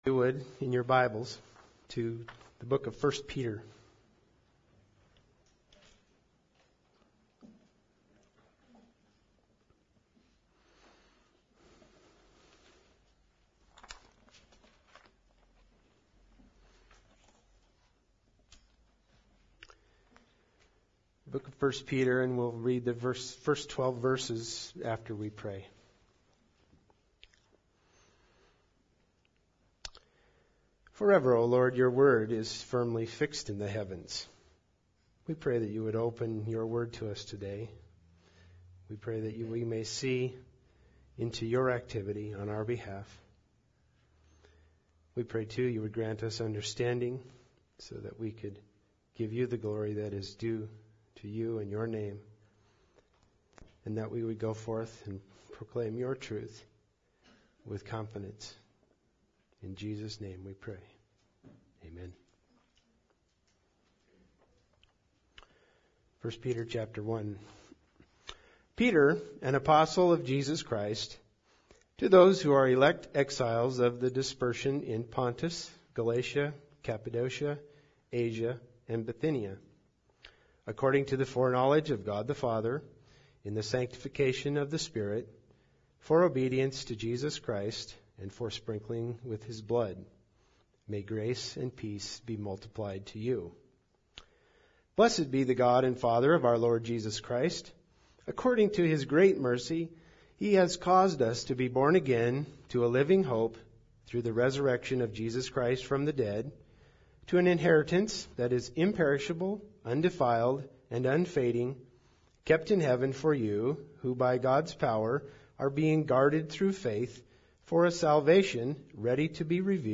1 Peter 1:1-14 Service Type: Sunday Service Bible Text